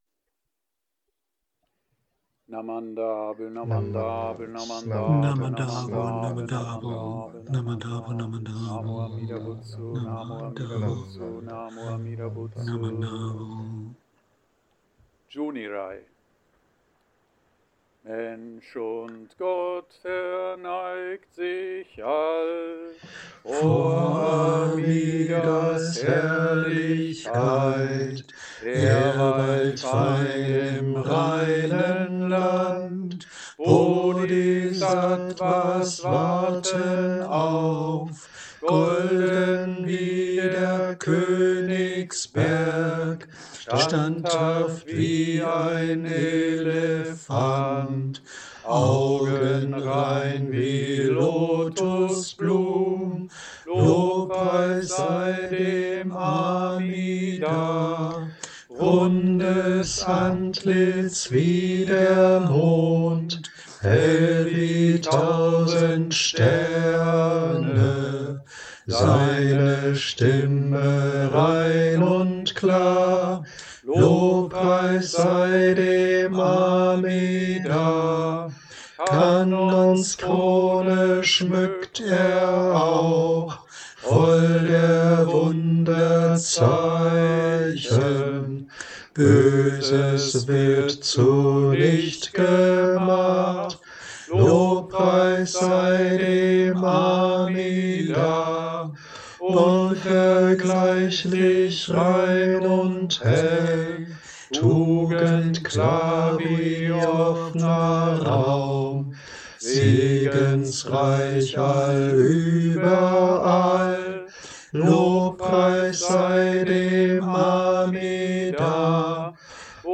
Aufgrund seiner Kürze ist wohl Juseige unter deutschen Shin-Buddhisten als japanischer Andachtstext gängiger, aber ich mag Junirai immer sehr gerne, vielleicht auch, weil man es eher singt als nur taktvoll rezitiert.
So war es dann auch nicht mehr weit bis zur Idee, eine singbare deutsche Fassung der „Zwölf Verehrungen“ zu realisieren.